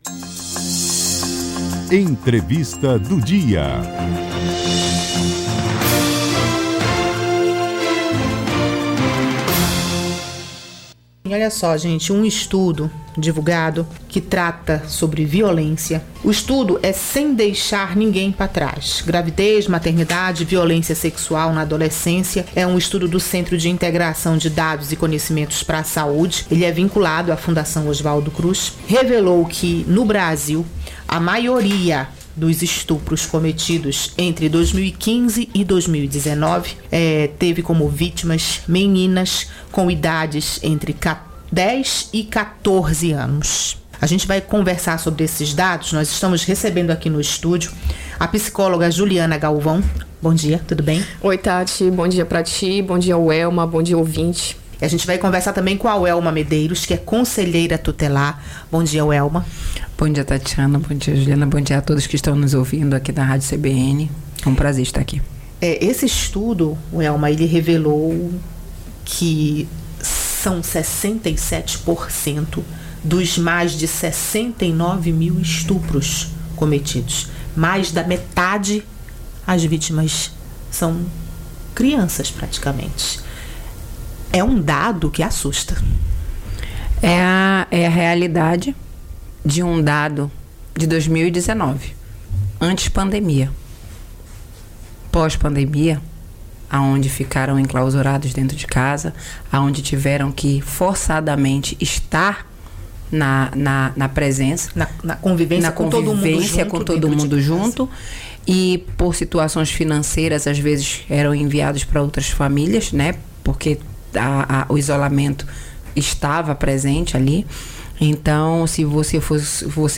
ENTREVISTA_DO_DIA_